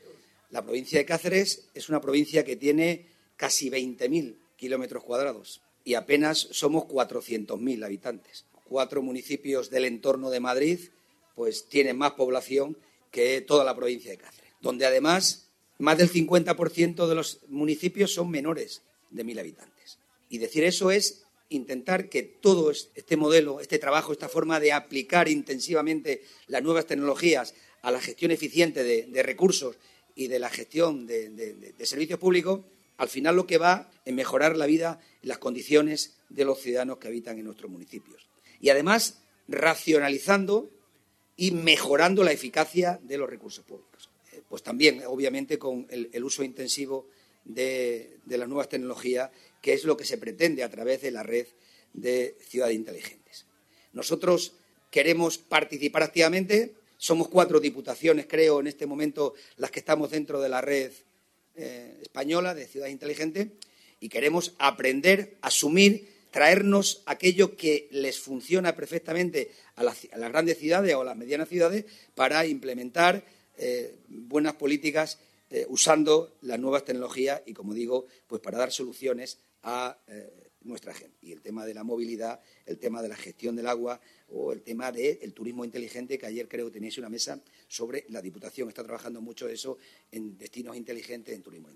08/05/2024, Cáceres.-La tecnología tiene que ser el gran aliado del territorio rural”, esta es una de las ideas que ha sobrevolado este martes y este jueves en las jornadas que se están celebrando en el Complejo Cultural San Francisco de Cáceres: el I Evento Territorio Inteligente, que organiza la Diputación junto a la Red Española de Ciudades Inteligentes (RECI) y el Ministerio de Transición Ecológica y Reto Demográfico.
CORTES DE VOZ